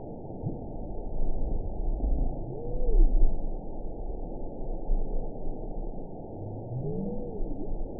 event 922338 date 12/30/24 time 02:01:56 GMT (5 months, 2 weeks ago) score 9.03 location TSS-AB10 detected by nrw target species NRW annotations +NRW Spectrogram: Frequency (kHz) vs. Time (s) audio not available .wav